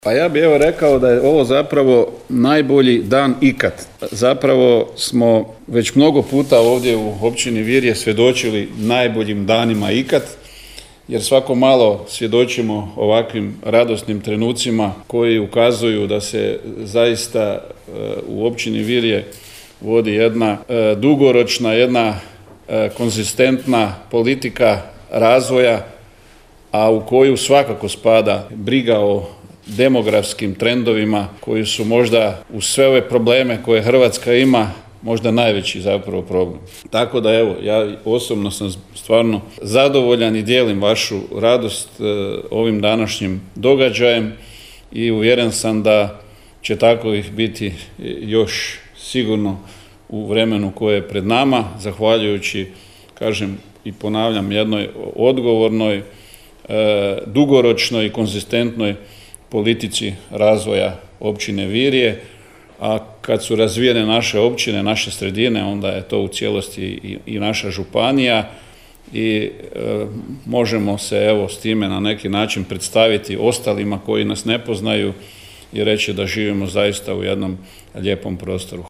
U Virju je održana završna konferencija projekta dogradnje i opremanja dječjeg vrtića Zrno s jaslicama u Virju.
Čestitkama se pridružio i župan KKŽ Darko Koren: